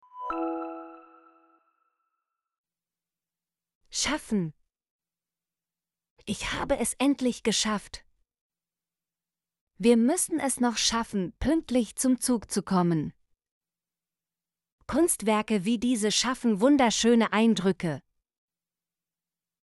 schaffen - Example Sentences & Pronunciation, German Frequency List